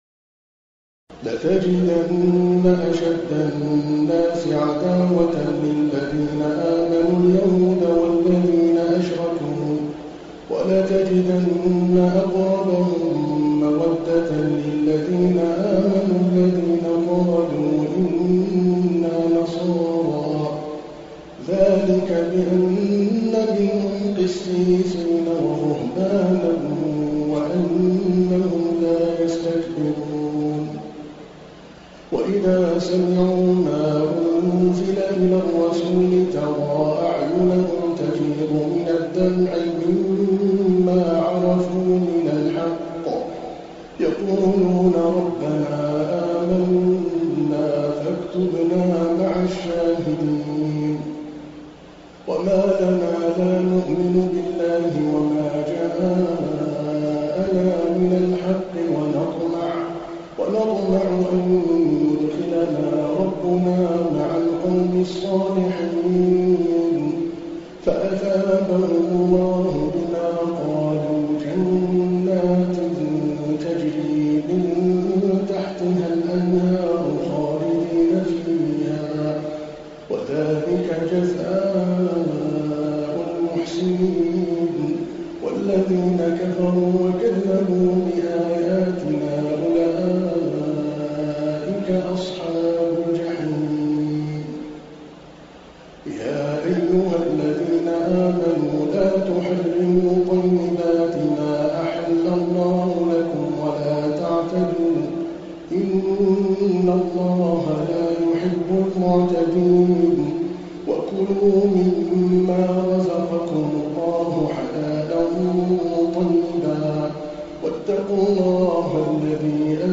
تراويح الليلة السابعة رمضان 1429هـ من سورة المائدة (82-108) Taraweeh 7 st night Ramadan 1429H from Surah AlMa'idah > تراويح الحرم المكي عام 1429 🕋 > التراويح - تلاوات الحرمين